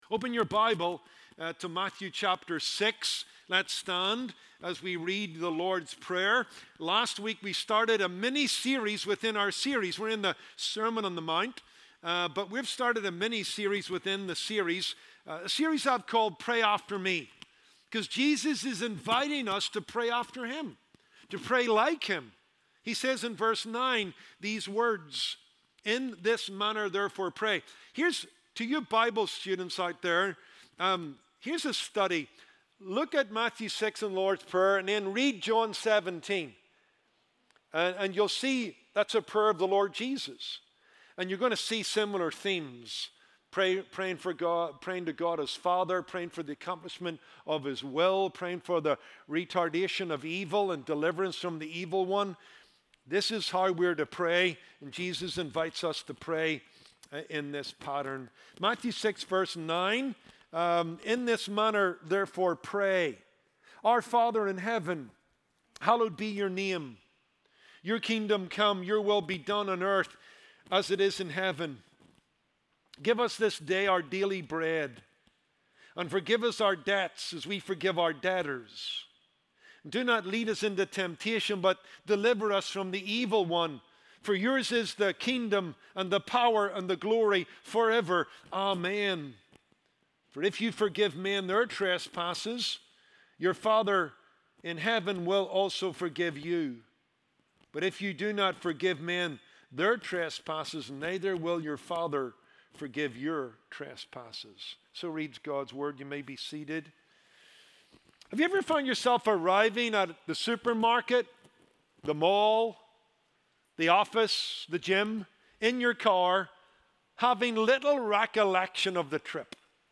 Sermons from the Pulpit of Kindred Community Church